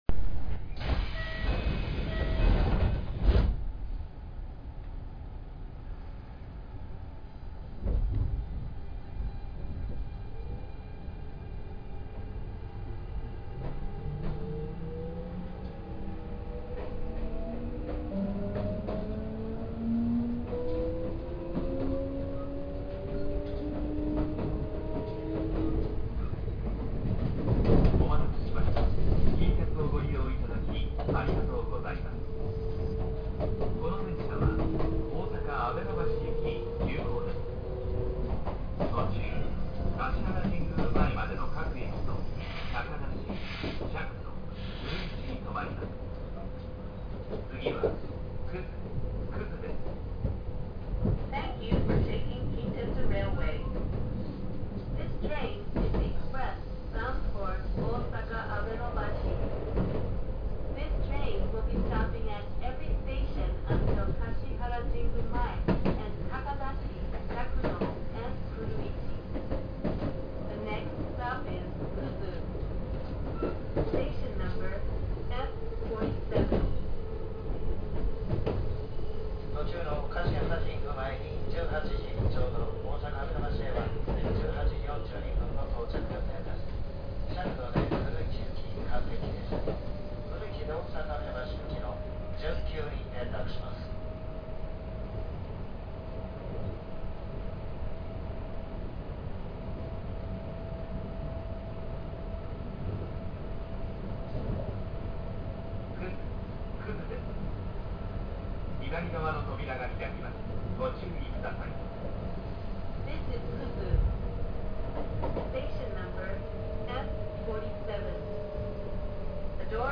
・6620系走行音
【吉野線】吉野口〜葛（2分29秒：1.14MB）
日立GTOの後期型となりますが、よく聞くものよりも重々しい音になっている印象です。近鉄のこの時期の車両としてはありがちな音で、珍しい物ではありません。